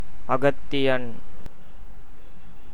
pronunciation) is an Indian film director, screenwriter, producer, lyricist, and actor.[2] He was the first director in Tamil cinema to win the National Film Award for Best Direction for Kadhal Kottai (1996).[3] For the film, he also won National Film Award for Best Screenplay and Filmfare Award for Best Director – Tamil.
Ta-அகத்தியன்.ogg.mp3